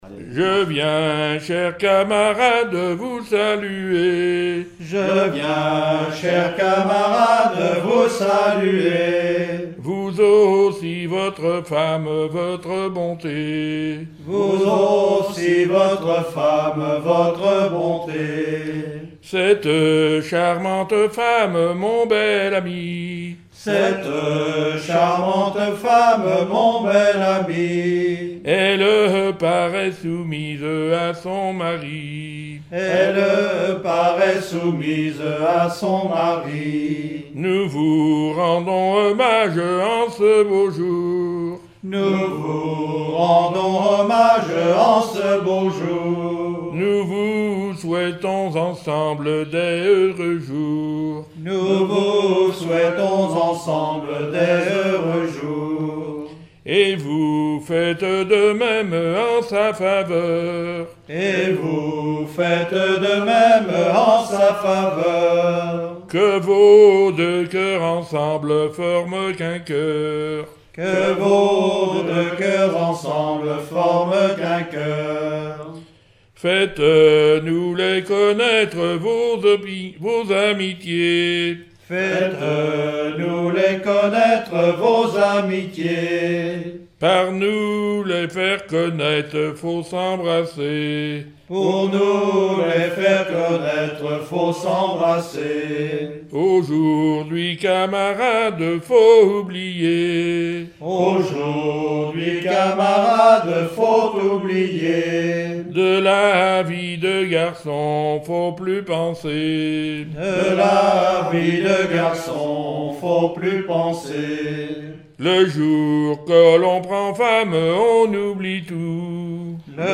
Genre strophique
Collectif cantonal pour le recueil de chansons
Pièce musicale inédite